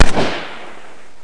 weapons&explosions
ruger.mp3